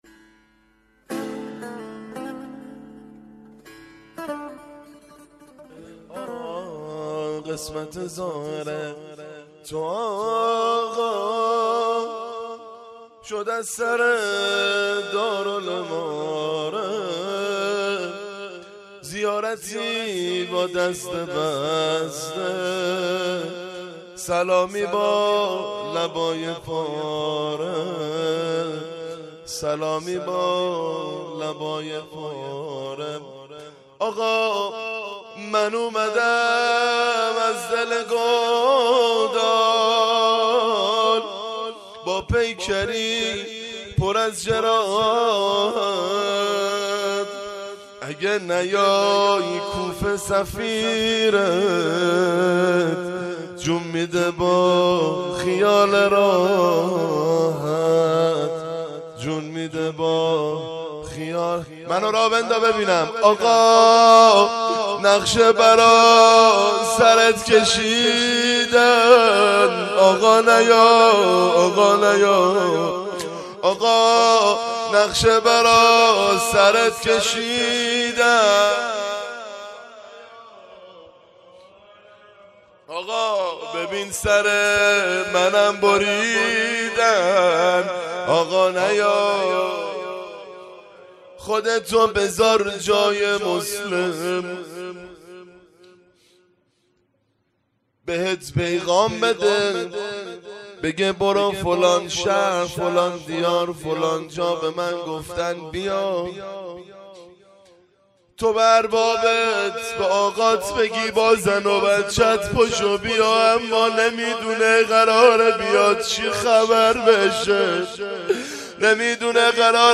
شب اول محرم 1396 - روضه